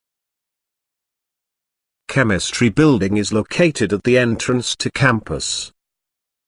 You will hear a sentence.